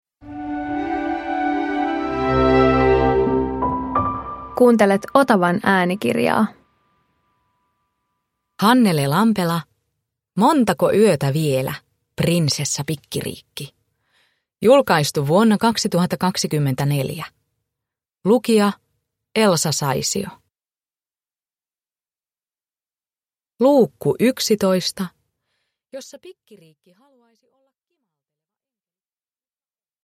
Montako yötä vielä, Prinsessa Pikkiriikki 11 – Ljudbok
Uppläsare: Elsa Saisio